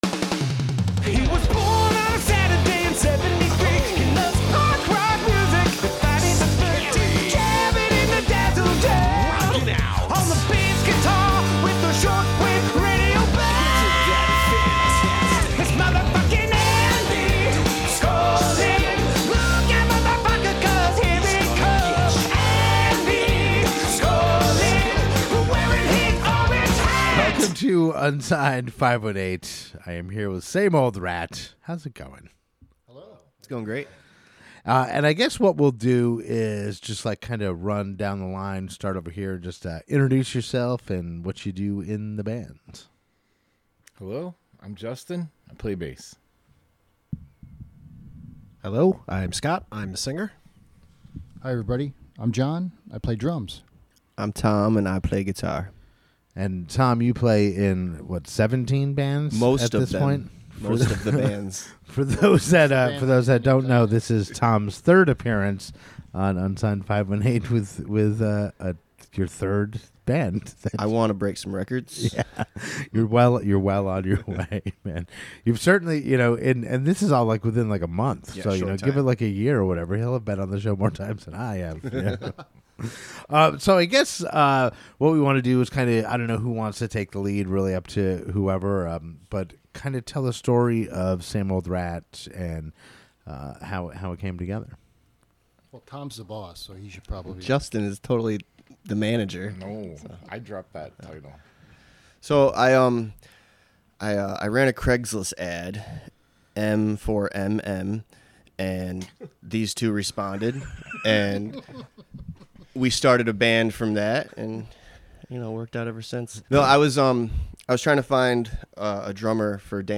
Same Old Rat came by the Dazzle Den to tell the story of the band, how they came together, where the name came from and more. We also get sidetracked and laugh a bunch.